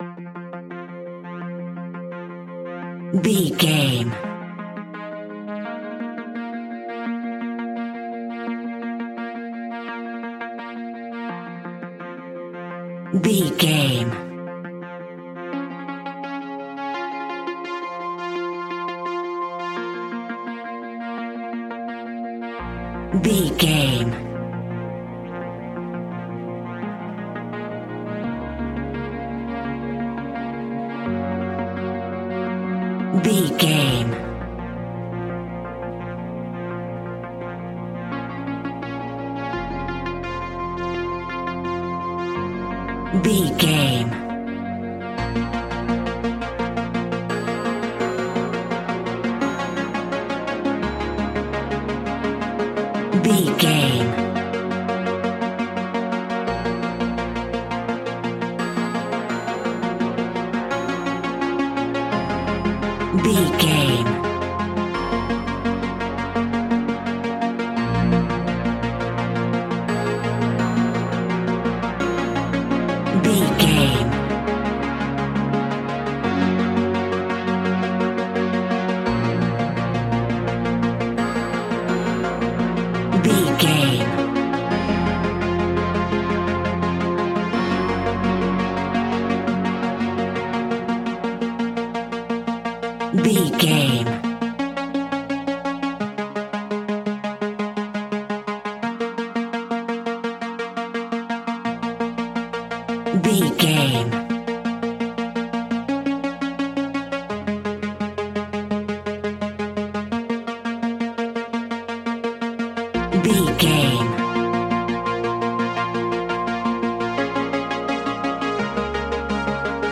In-crescendo
Thriller
Aeolian/Minor
ominous
dark
suspense
haunting
eerie
horror music
Horror Pads
horror piano
Horror Synths